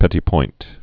(pĕtē point)